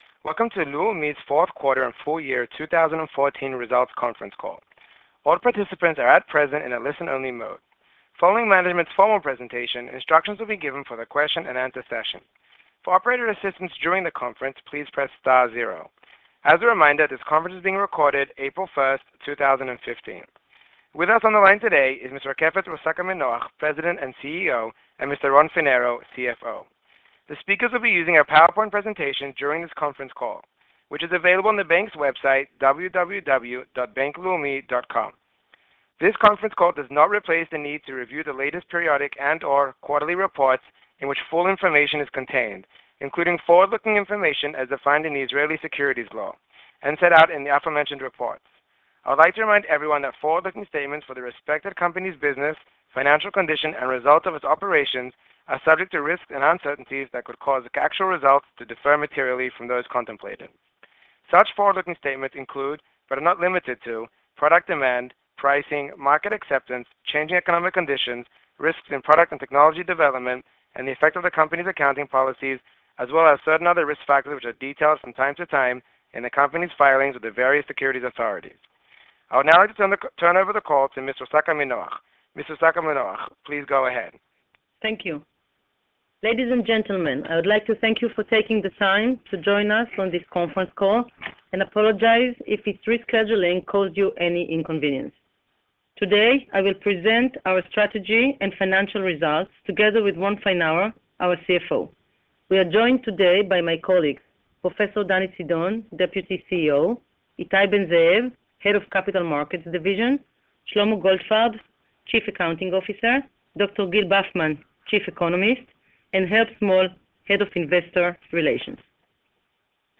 Investor Presentation and Conference Call
Bank_Leumi_Q4_2014_Results_Conference_Call.wma